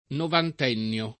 vai all'elenco alfabetico delle voci ingrandisci il carattere 100% rimpicciolisci il carattere stampa invia tramite posta elettronica codividi su Facebook novantennio [ novant $ nn L o ] s. m.; pl. -ni (alla lat. ‑nii )